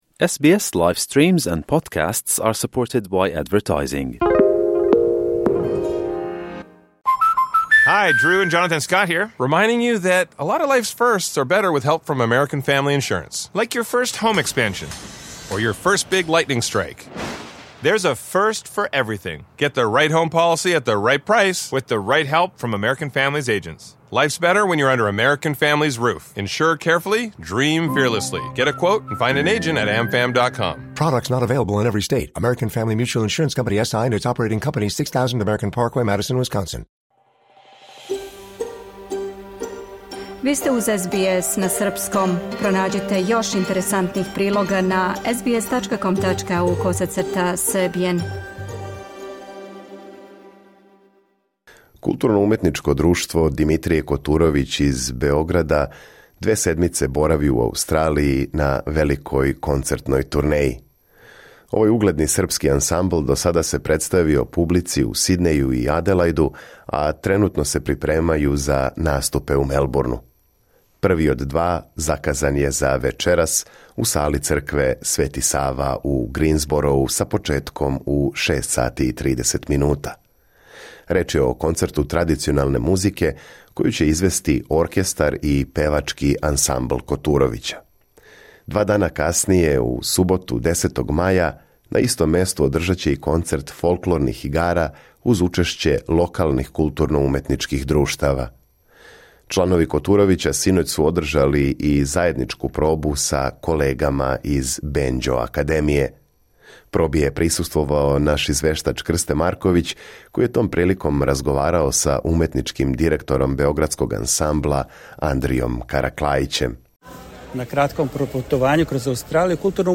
For more stories, interviews, and news from SBS SERBIAN, explore our podcast collection here.